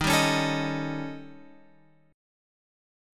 D#7b5 chord {11 10 11 x 10 11} chord